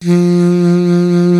55af-sax02-F2.wav